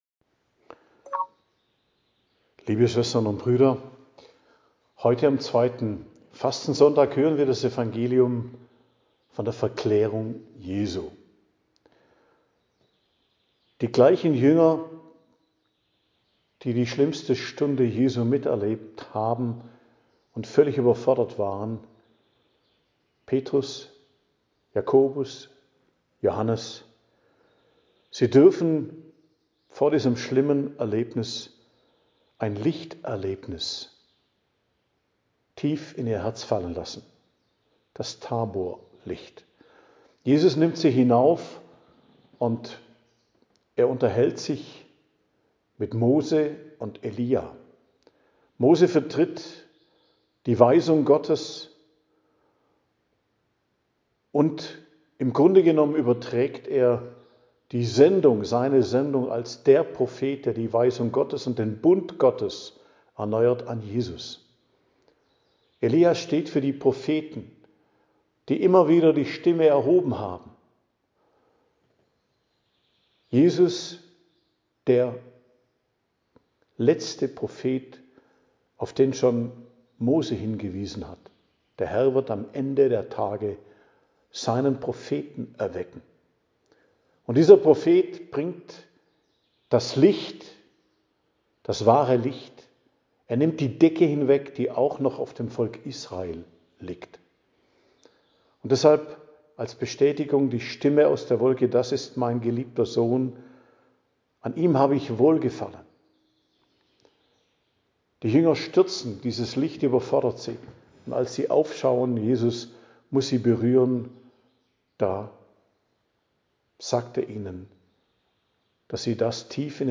Predigt zum zweiten Fastensonntag, 1.03.2026